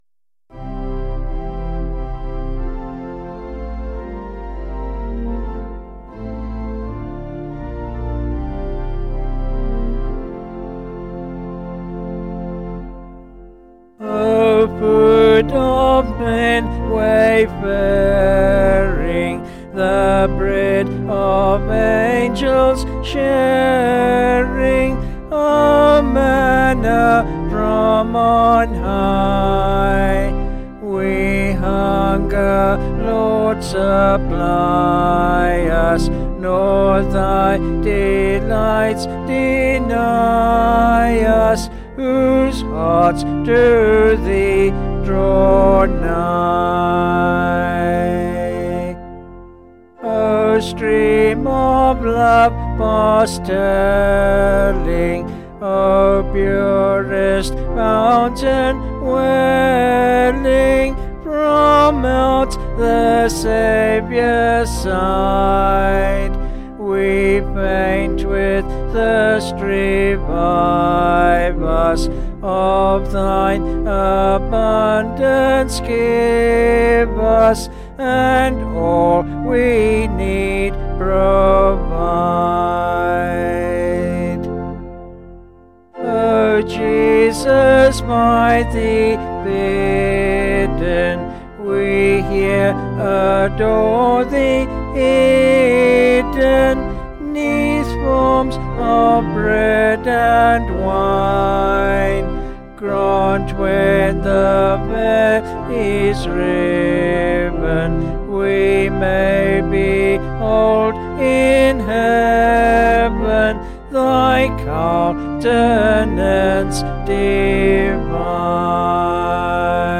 Vocals and Organ   702.5kb Sung Lyrics